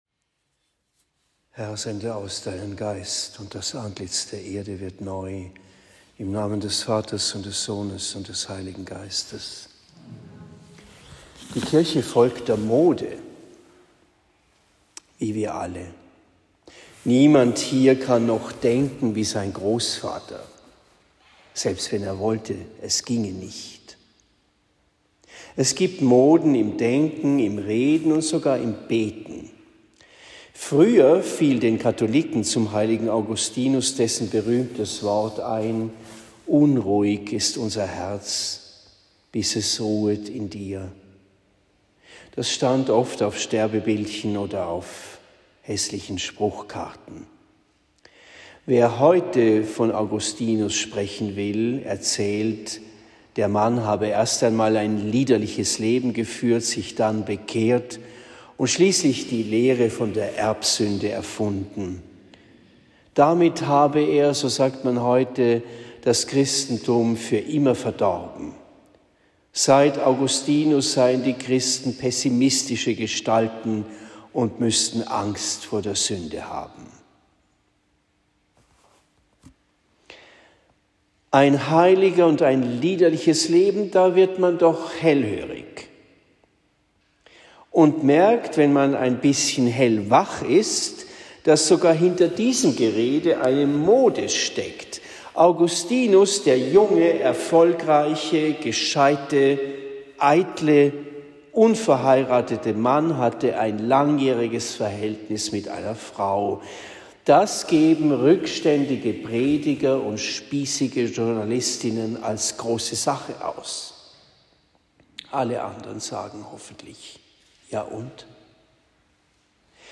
Predigt in Bischbrunn am 28. August 2023